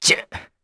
Zafir-Vox_Attack1_kr.wav